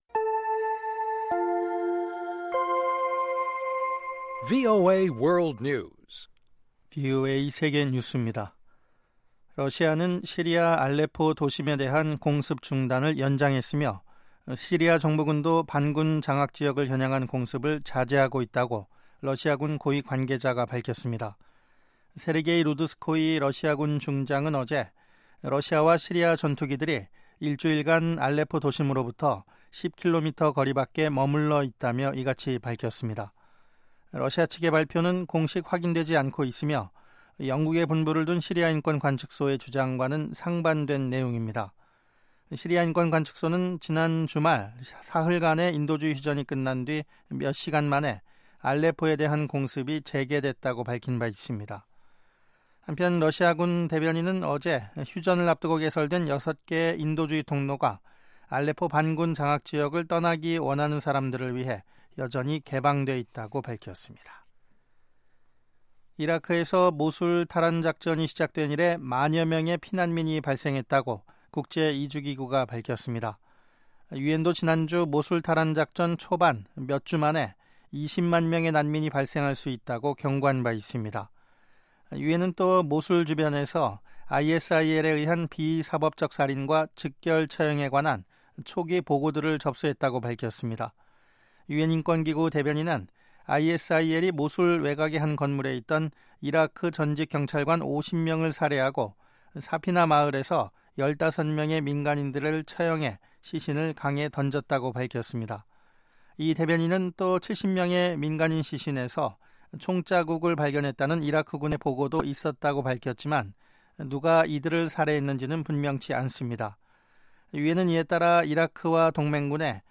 생방송 여기는 워싱턴입니다